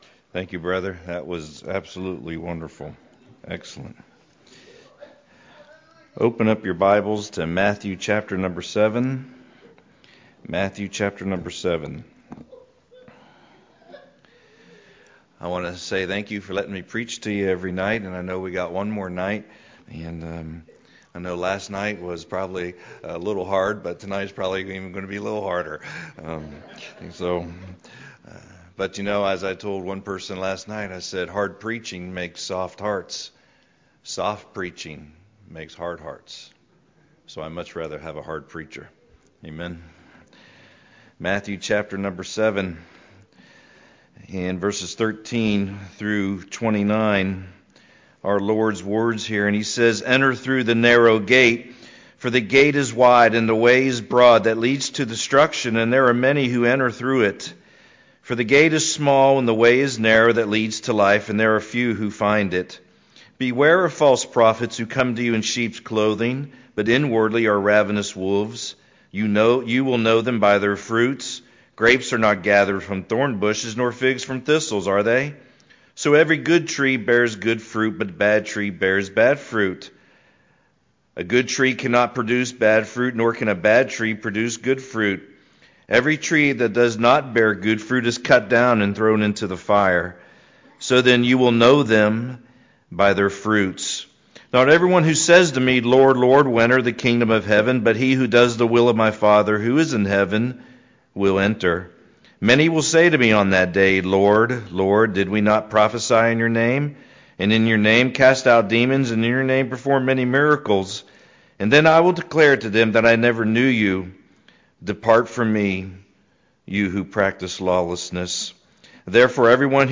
Fall 2018 Revival